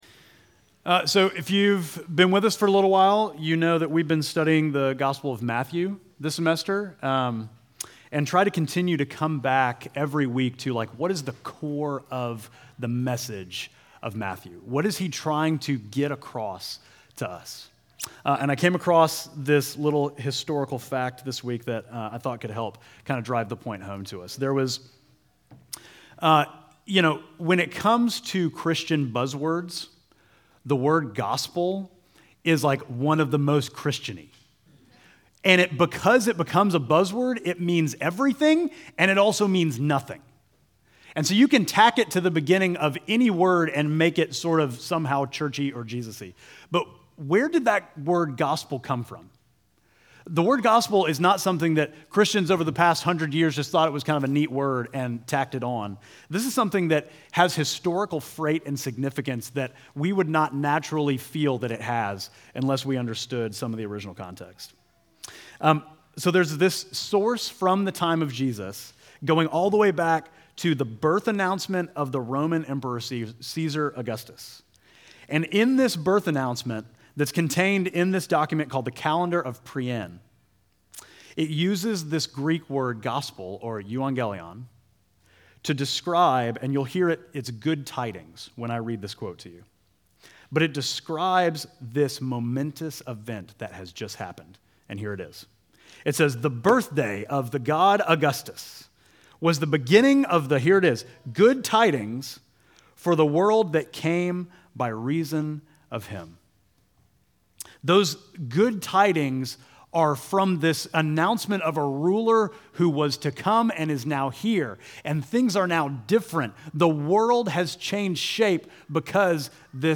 Midtown Fellowship Crieve Hall Sermons Responses to The King: Are You The One?